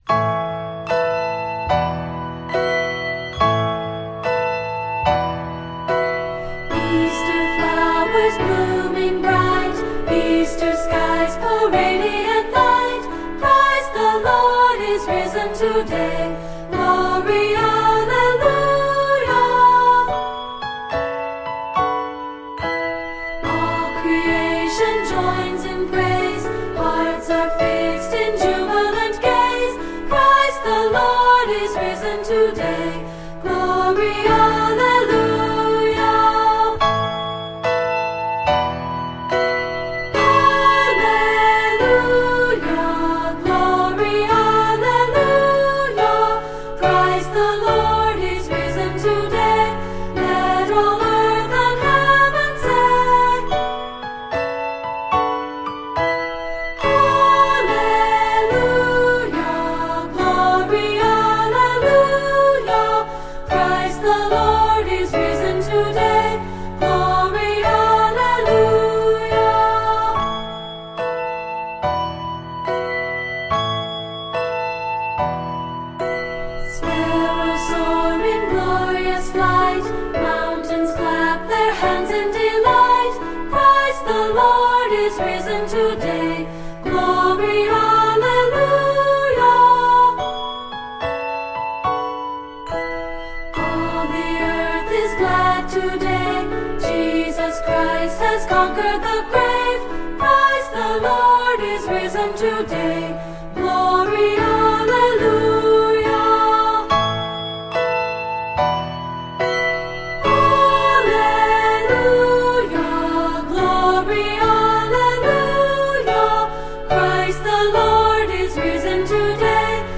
Voicing: Unison